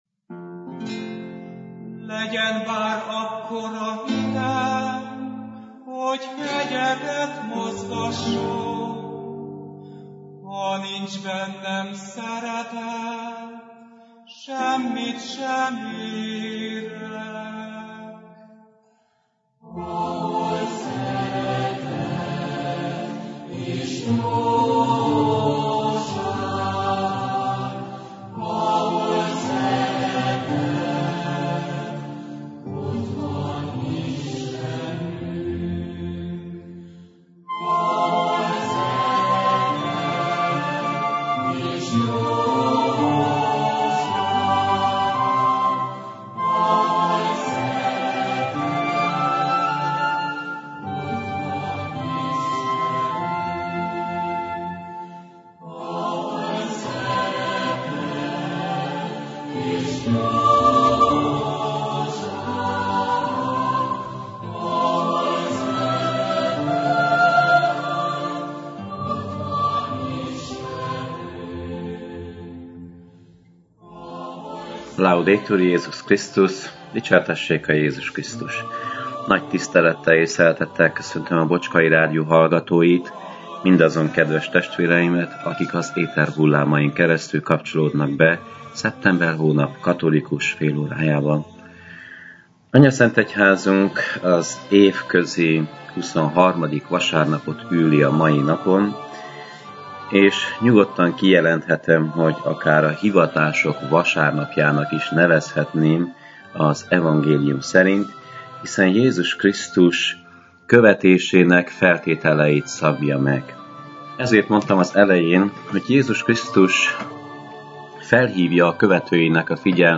Igét hirdet
a clevelandi Szent Imre Katolikus Templomból.